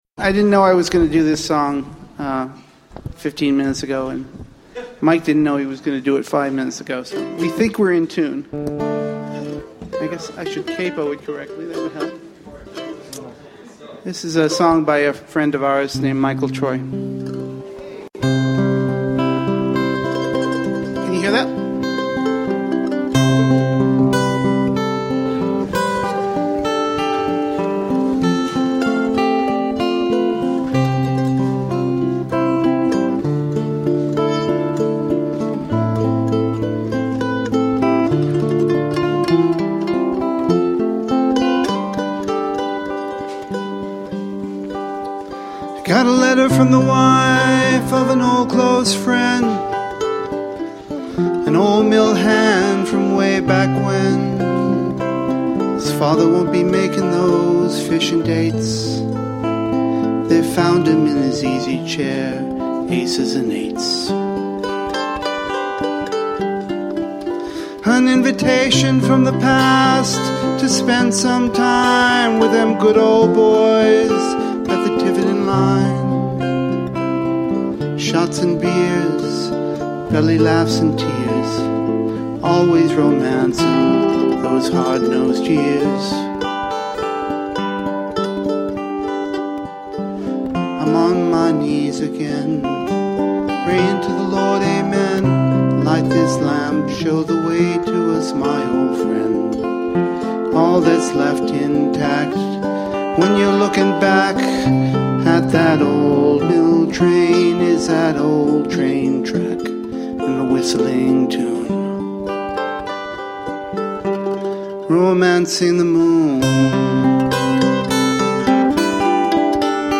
I LOVE to play mandolin.
2/13/12: Here's a live